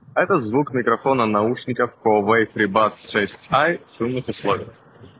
Микрофон Huawei Freebuds 6i отличного качества на 9 / 10.
В шумных условиях:
huawei-freebuds-6i-shum.m4a